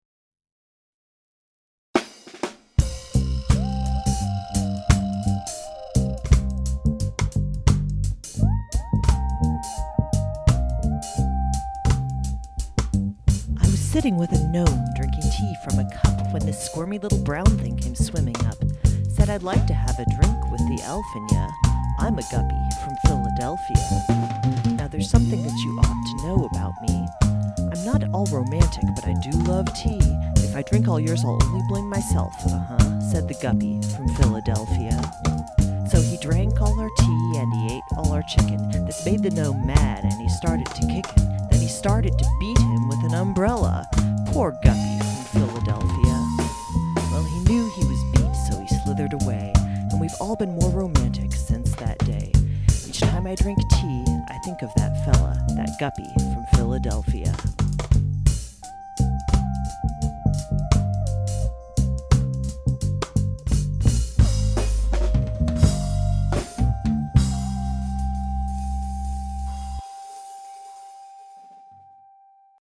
Here's a nifty audio version I made (set to reggae loops via Magic Garage Band) so you can hear the tortured meter: